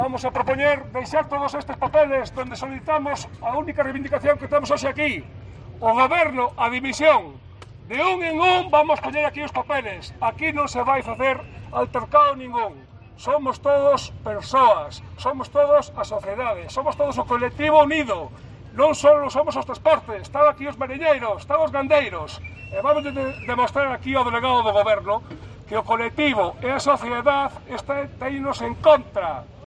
Manifestación transportistas A Coruña